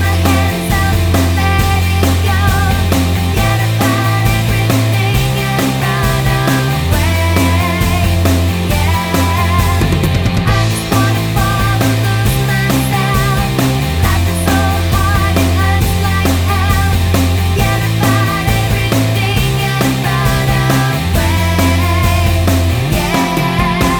Indie / Alternative